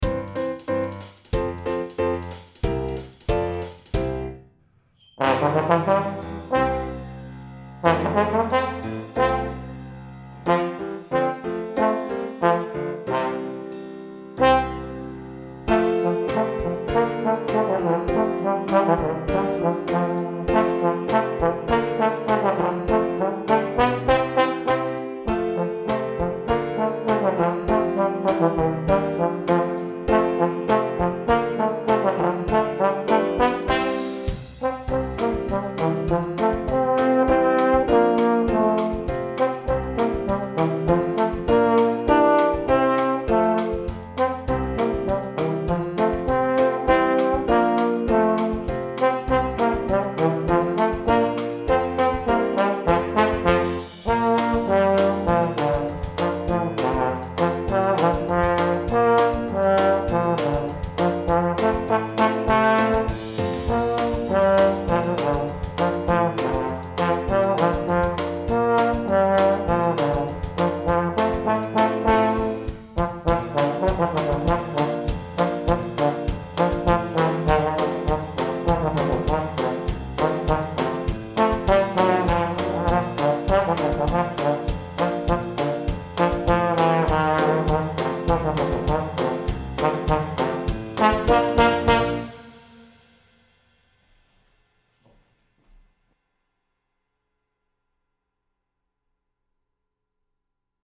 TROMBONE SOLO